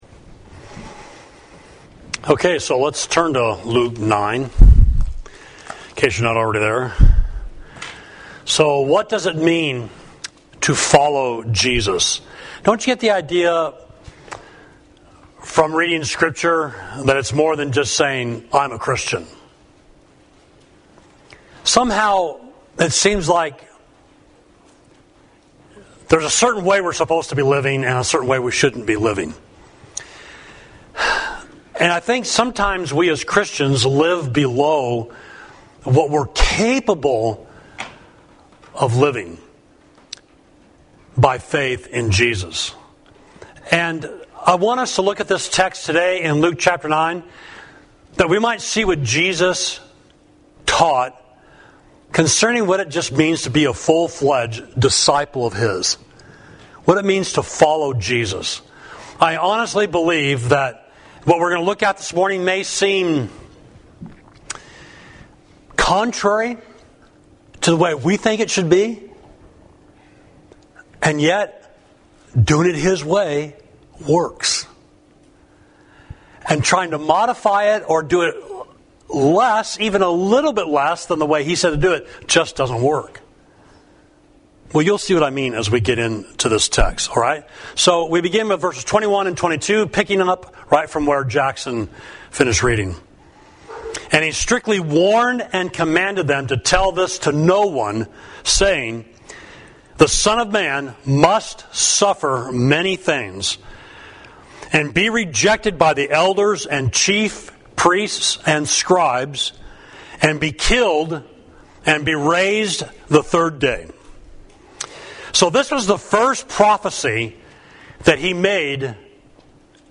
Sermon: Do You Wish to Follow Jesus? Luke 9.21–43a